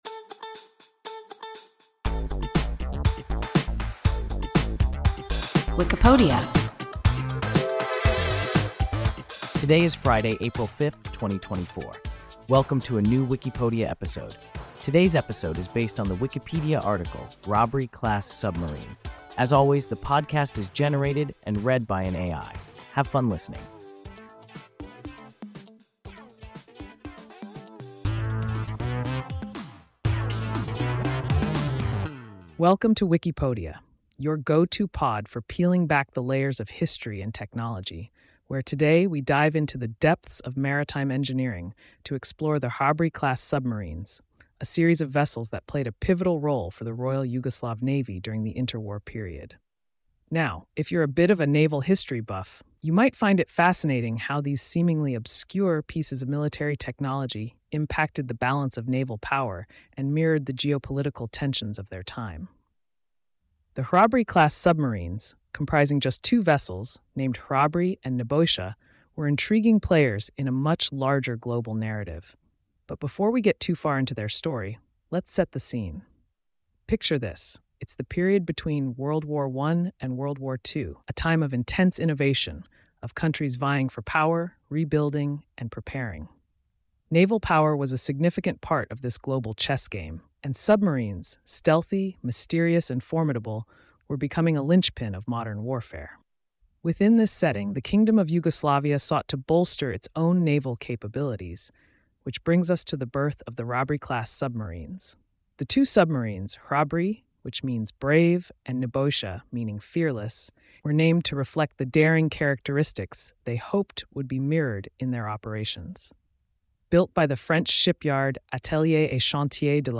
an AI podcast